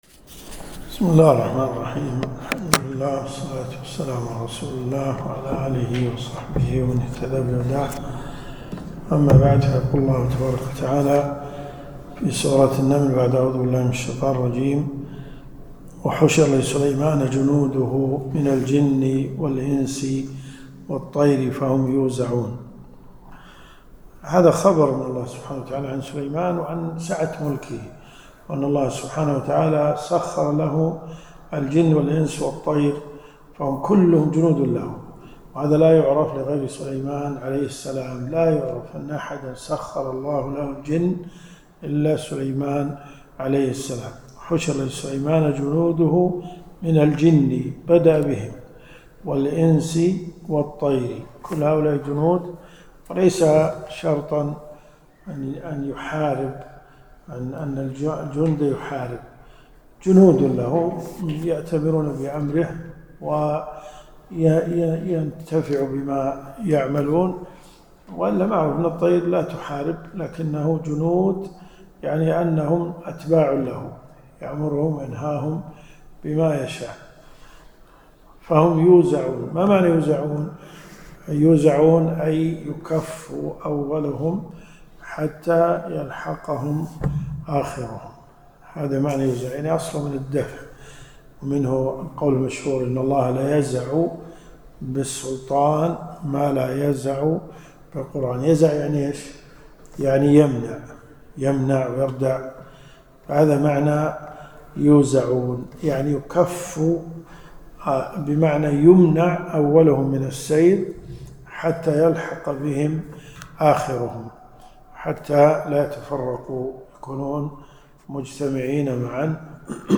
دروس صوتيه ومرئية تقام في جامع الحمدان بالرياض